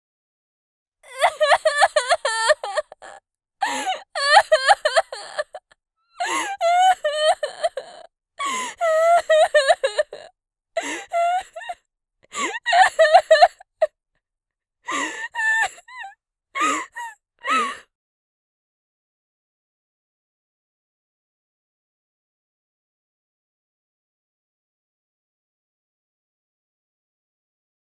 جلوه های صوتی
دانلود آهنگ گریه 2 از افکت صوتی انسان و موجودات زنده
دانلود صدای گریه 2 از ساعد نیوز با لینک مستقیم و کیفیت بالا